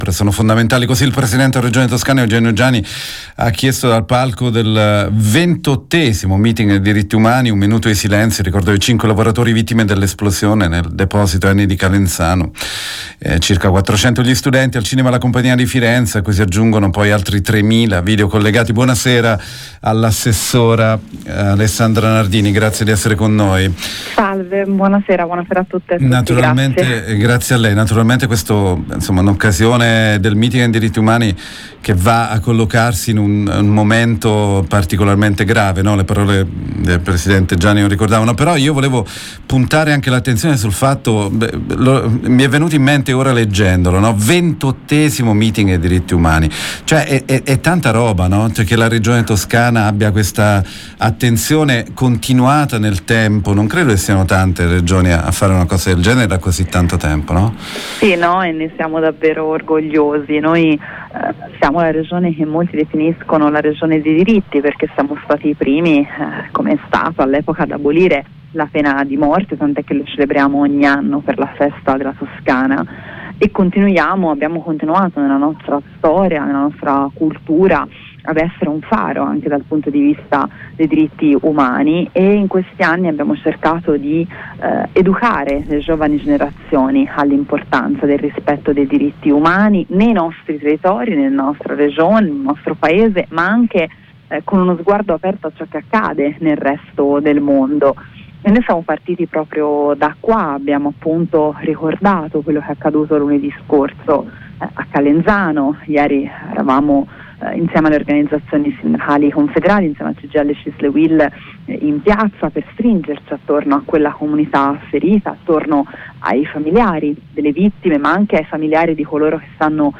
Intervista con l’assessora all’istruzione Alessandra Nardini